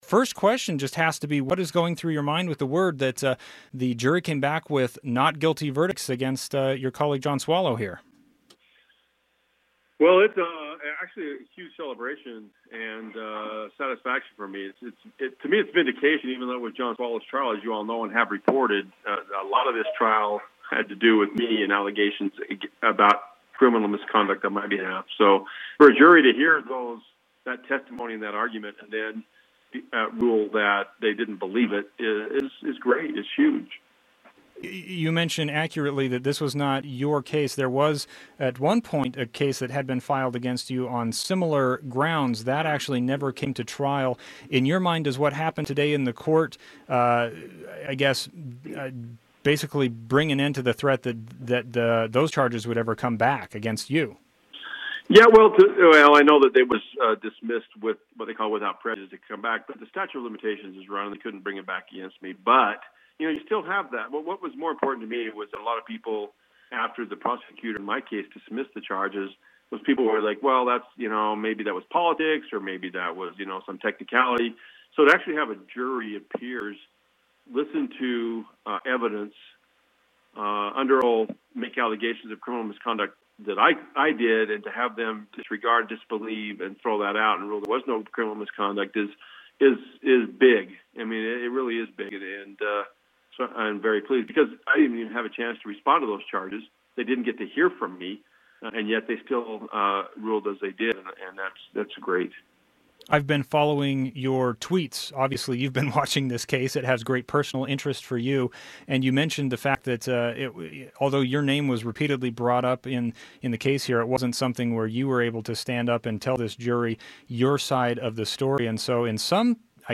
Former Utah Attorney General Mark Shurtleff became emotional when discussing the not guilty verdicts in the public corruption trial against the man who followed him in that office, John Swallow.